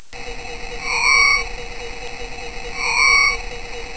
9. Black and White Warbler (at 1/6th speed)
This call is first played at its normal speed, and then at 1/6th its normal speed.
Black and White Warbler nocturnal flight call.